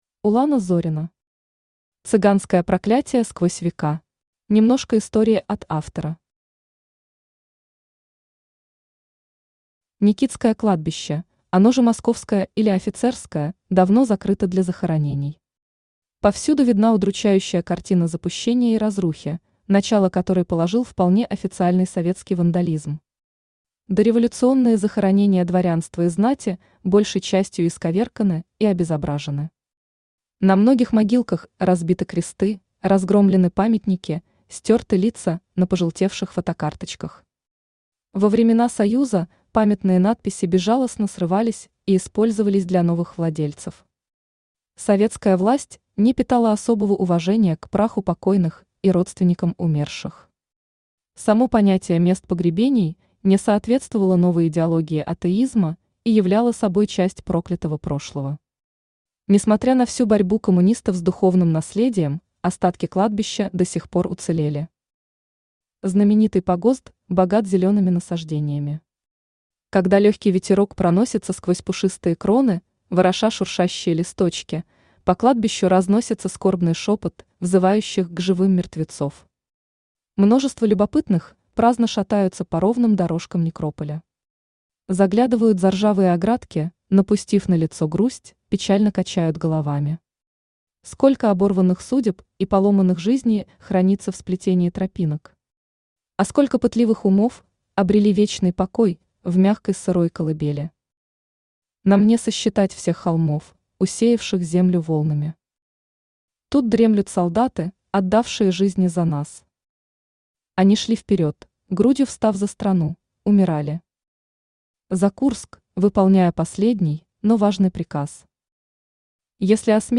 Автор Улана Зорина Читает аудиокнигу Авточтец ЛитРес.